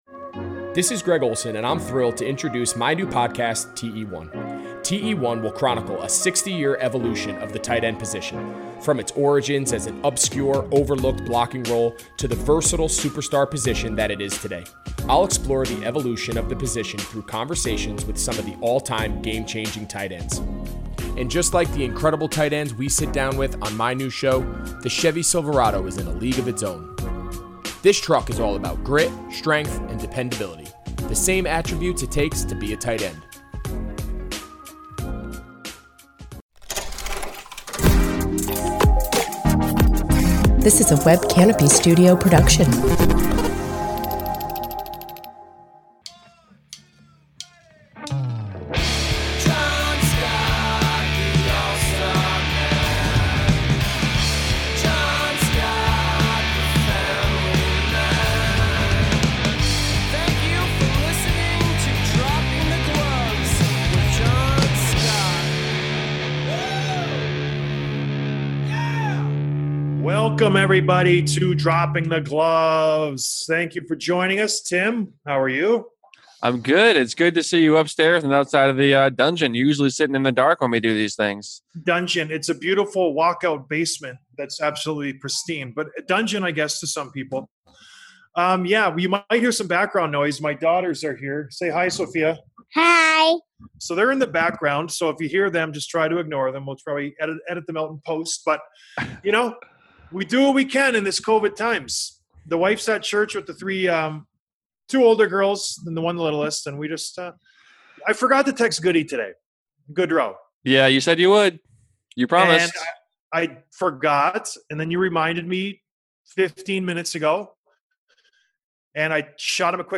Interview with Barclay Goodrow, Stanley Cup Champion
Please excuse the technical difficulties!